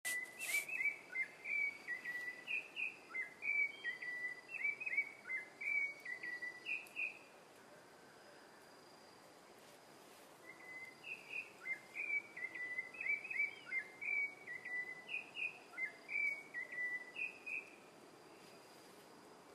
Zorzal Sabiá (Turdus leucomelas)
Nombre en inglés: Pale-breasted Thrush
Fase de la vida: Adulto
Provincia / Departamento: Misiones
Localidad o área protegida: Campo Ramón
Condición: Silvestre
Certeza: Vocalización Grabada
Canto-5.mp3